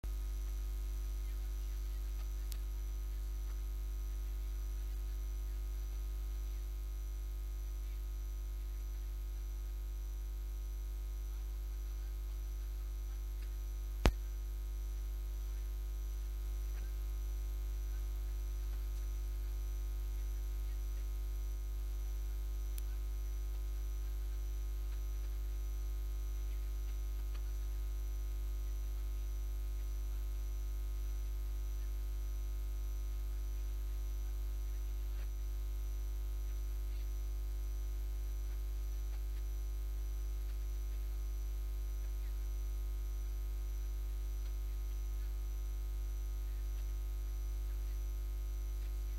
Durante el acto de entrega de los Premios Pablo Iglesias en la Caseta del PSOE en la Feria de Albacete
Cortes de audio de la rueda de prensa